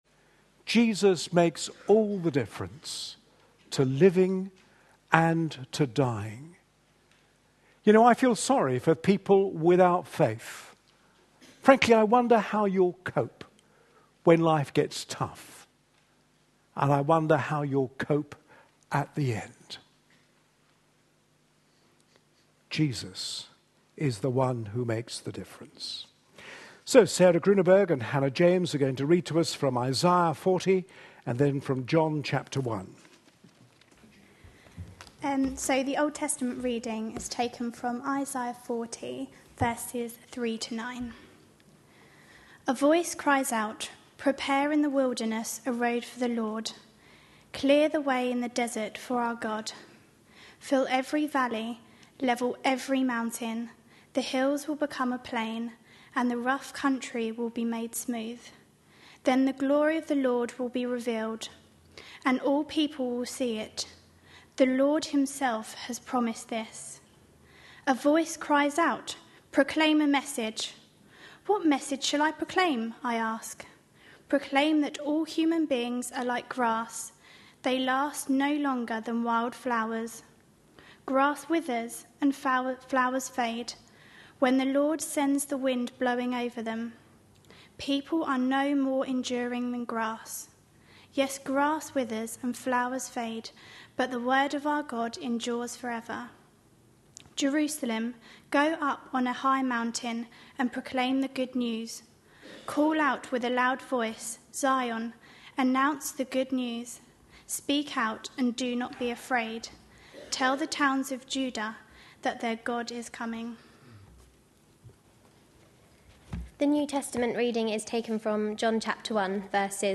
A sermon preached on 16th December, 2012.